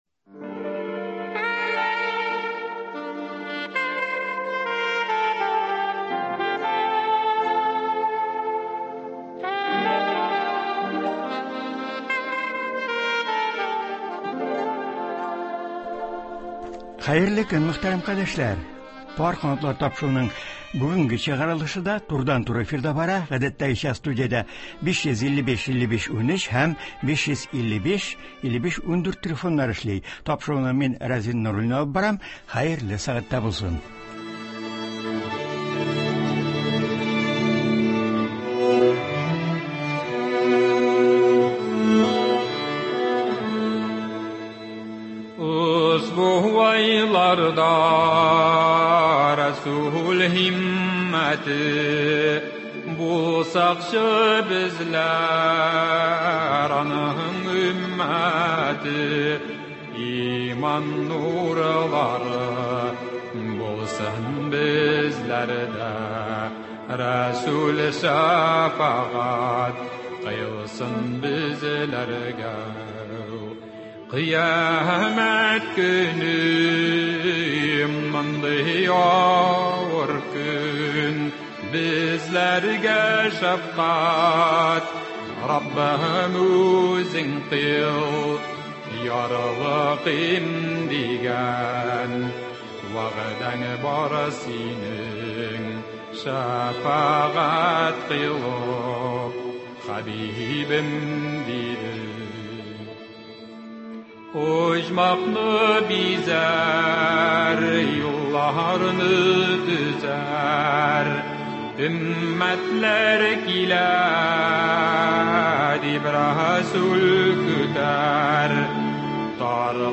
турыдан-туры эфирда татар гаиләсе традицияләре, Коръән табынын әзерләү, мәетне соңгы юлга озату шартлары турында сөйләячәк, тыңлаучылар сорауларына җавап бирәчәк.